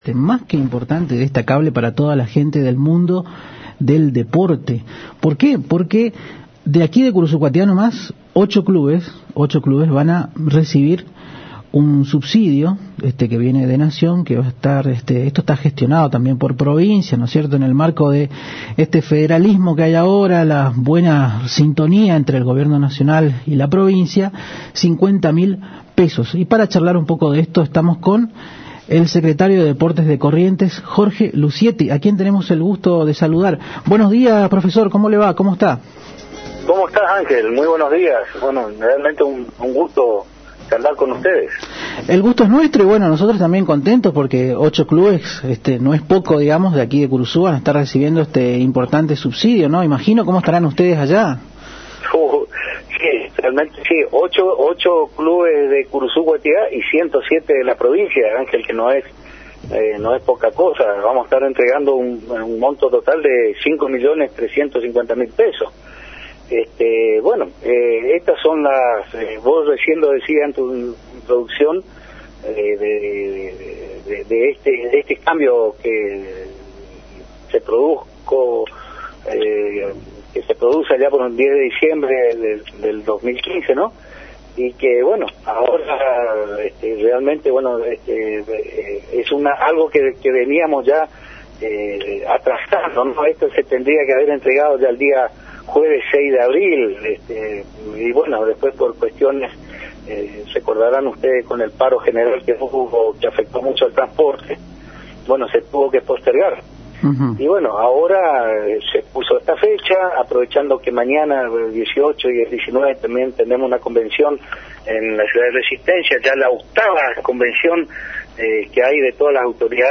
Este es el aut�ntico federalismo, se trabaja en forma mancomunada todas las provincias con el secretario de Deportes", se�al� Lucietti en contacto con Arriba Ciudad a trav�s de la AM 970 Radio Guarani .